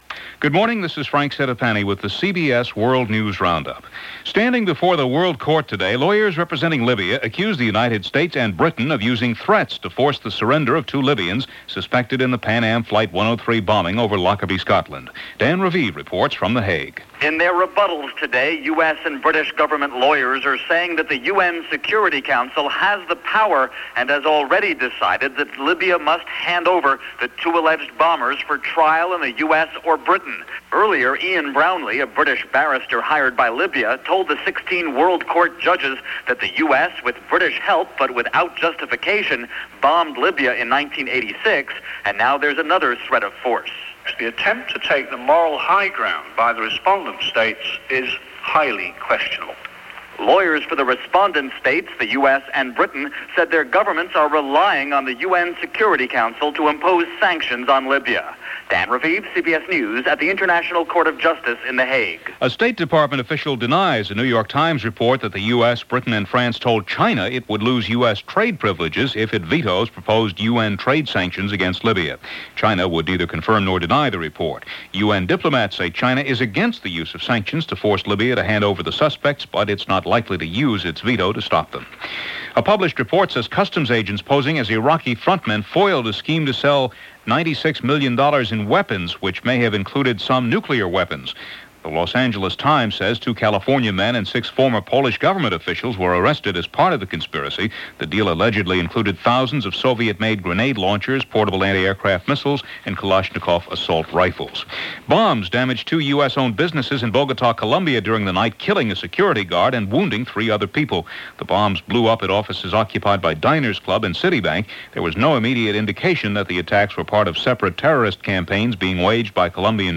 March 28, 1992 – CBS World News Roundup – Gordon Skene Sound Collection-